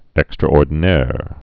(ĕkstrə-ôrdn-âr, -dē-nâr)